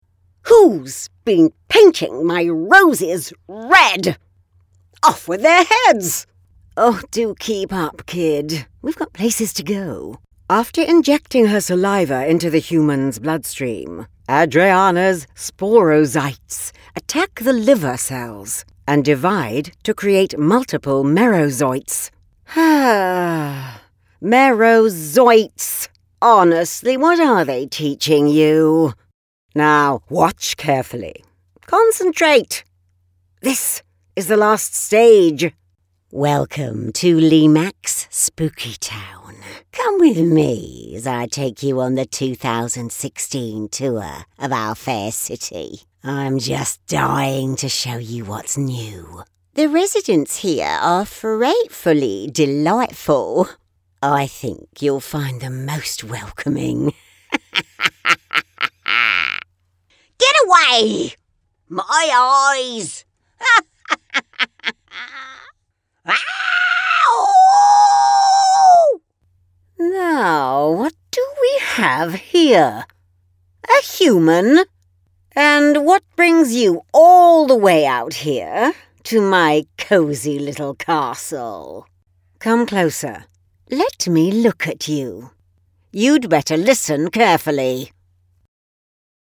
Mature Adult, Adult
I have a home studio and Source Connect.
british english
friendly
Witch-Characters-DEMO_.mp3